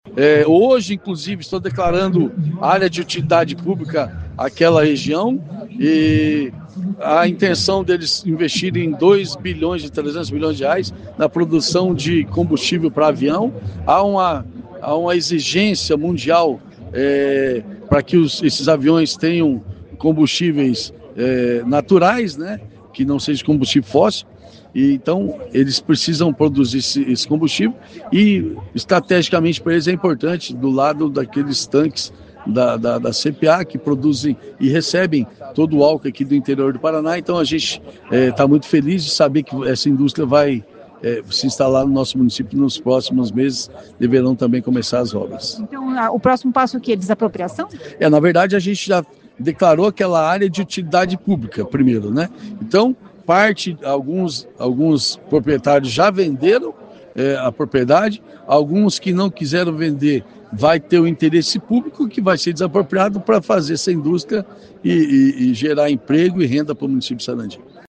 A empresa irá investir R$ 2,3 bilhões de reais no empreendimento. Ouça o que diz o prefeito sobre os próximos passos para a instalação da indústria.